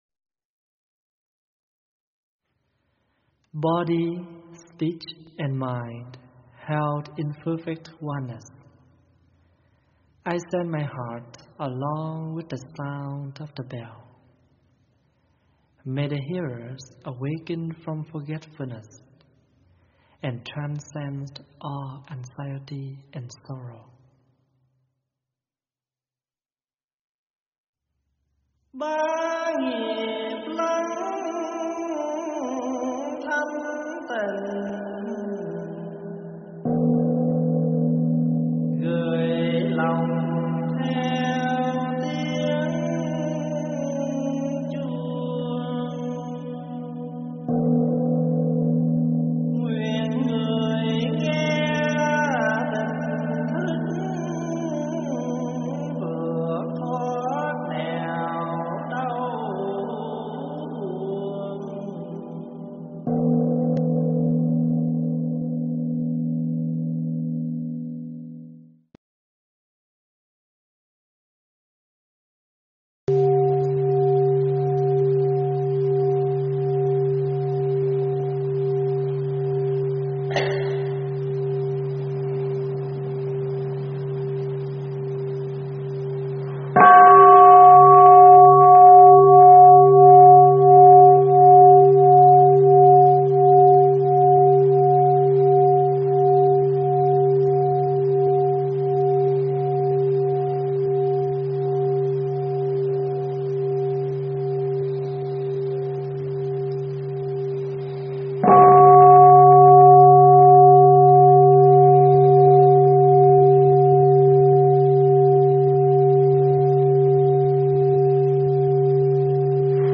Mp3 thuyết pháp Think Positive to Make Things Positive - ĐĐ.